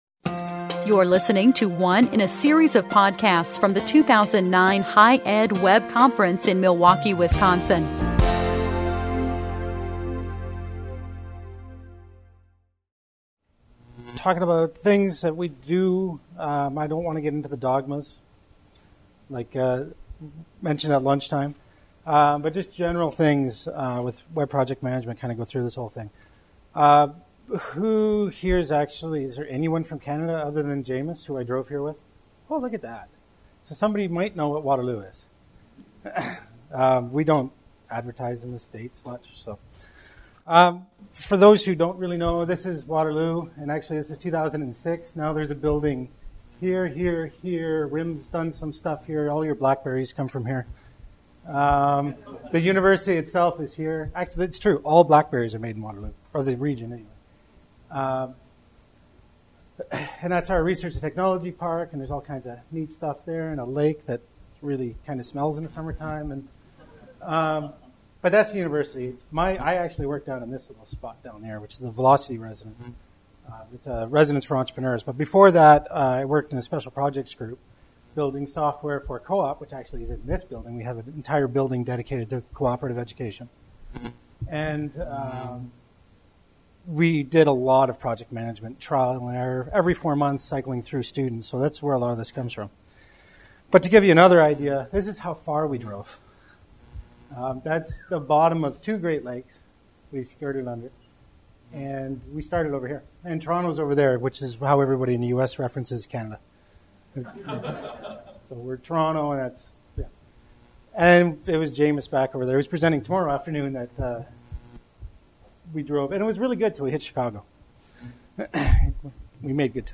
Session Details - HighEdWeb 2009 Conference: Open + Connected